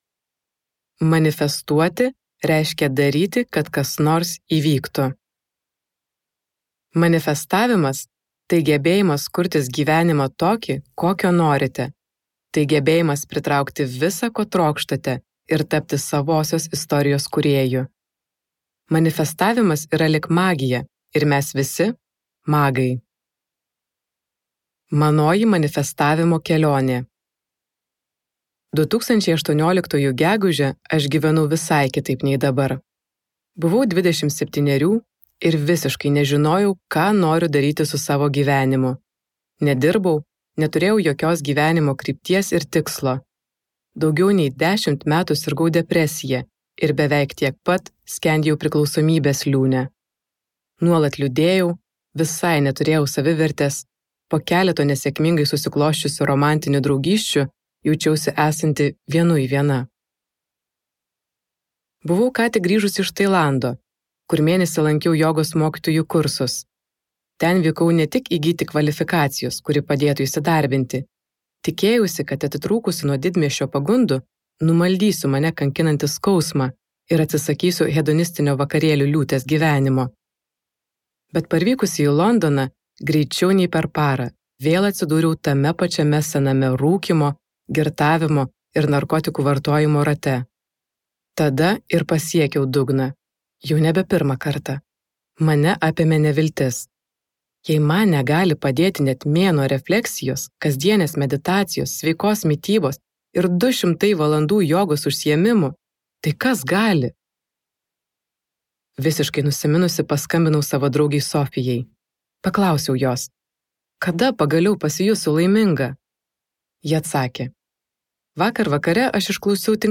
Audioknyga